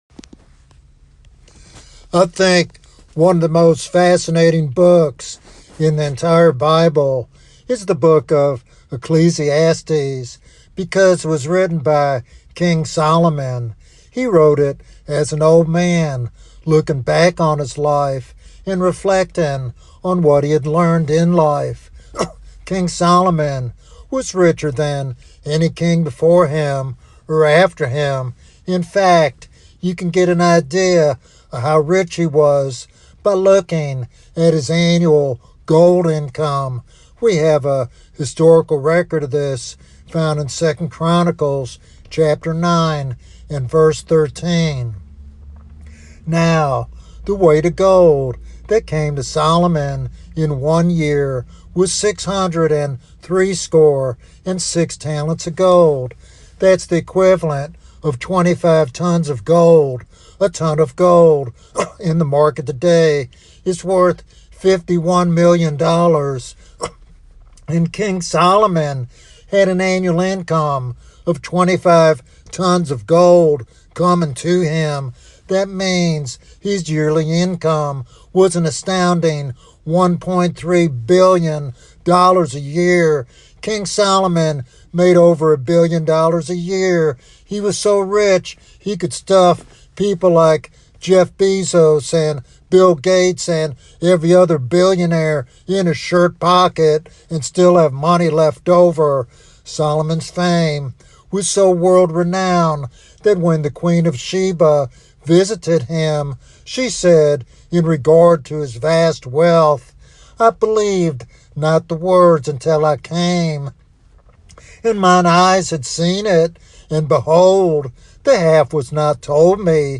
With vivid imagery and heartfelt urgency, he calls listeners to embrace the hope and mercy found only in Jesus Christ. This sermon challenges believers and seekers alike to consider their eternal destiny and respond to God's gracious invitation.